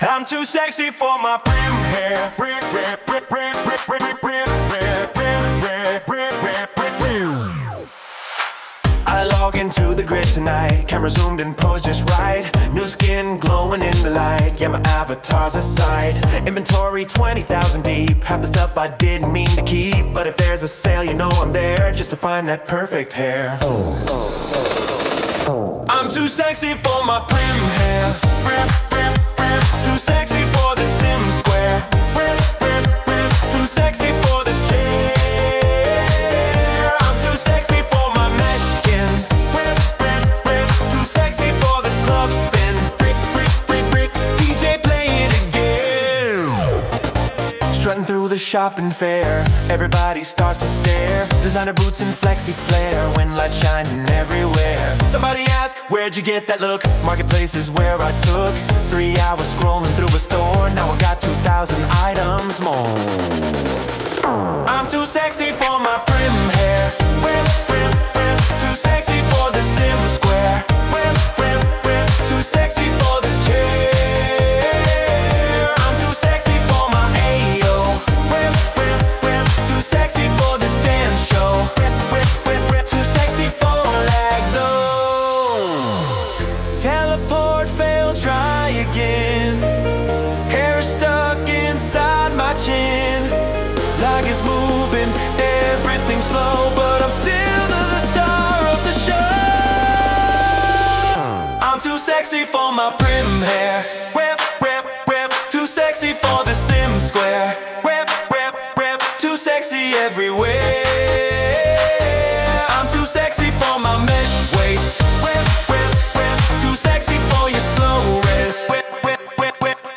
Second Life Music Parody Project